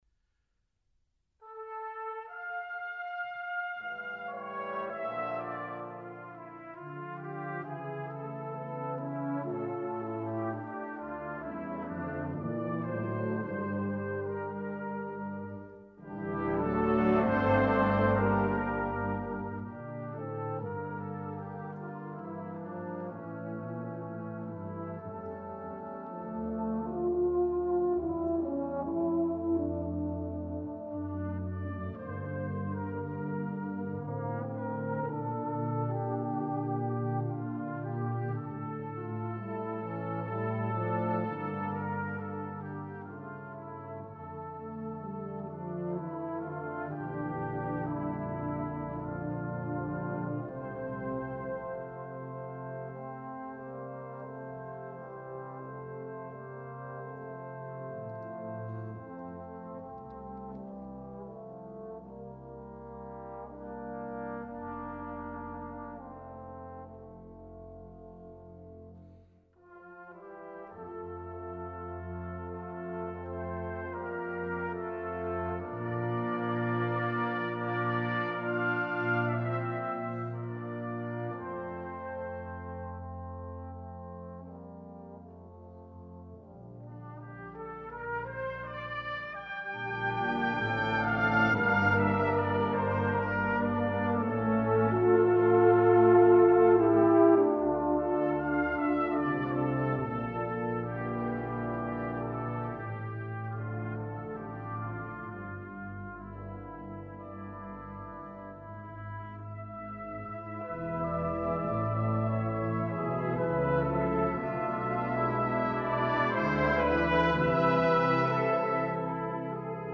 Salvation Army band piece